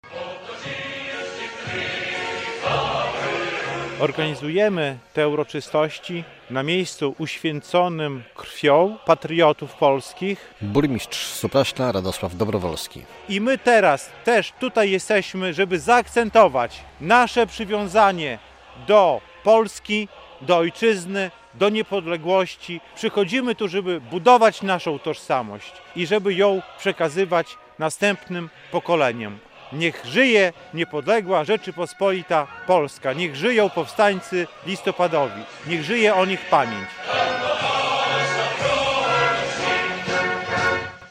Uroczystości w Kopnej Górze - relacja